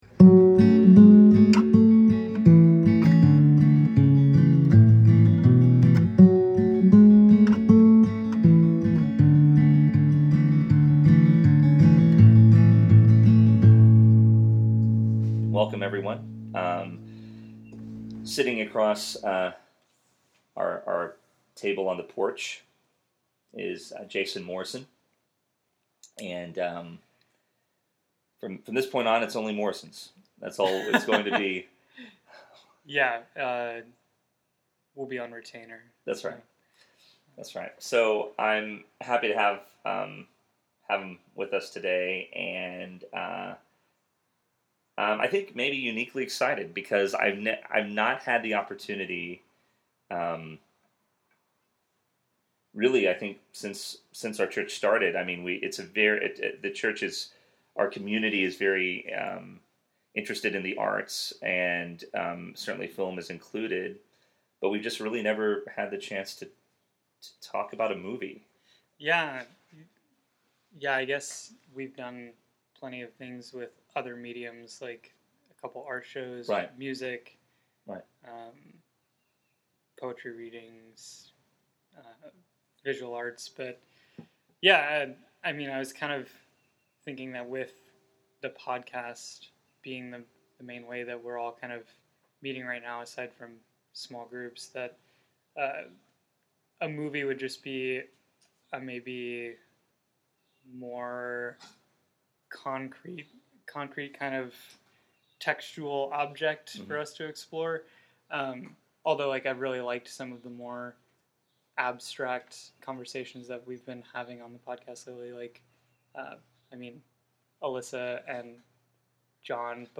Center Church SGF A Hidden Life | A Conversation